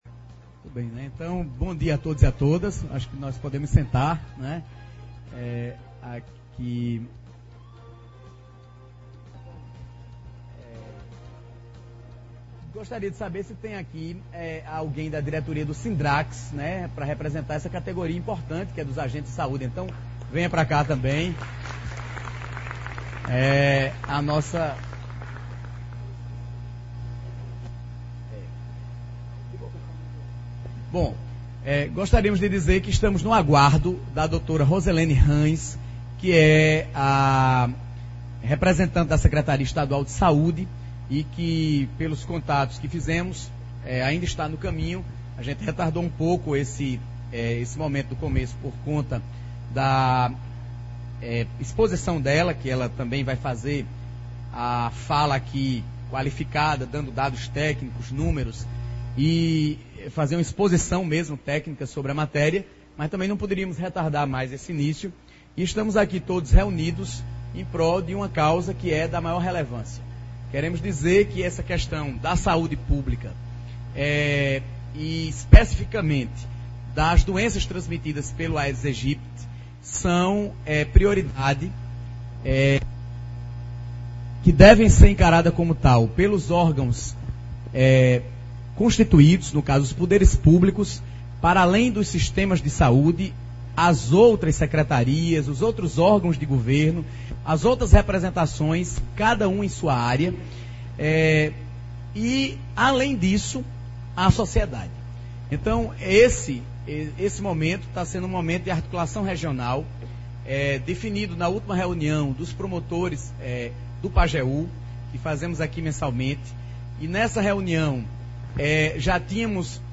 Hoje pela manhã no Cine Teatro São José de Afogados da Ingazeira, o Ministério Público da 3º circunscrição, que tem como coordenador o promotor Dr. Lúcio Luiz de Almeida Neto, promoveu uma Audiência Pública Regional para tratar e dar encaminhamentos ao combate do Aedes Aegypti, mosquito que tem causado muito transtorno, pois carrega com sigo o poder de proliferar doenças como a Dengue, a Febre chikungunya e o Zika Vírus.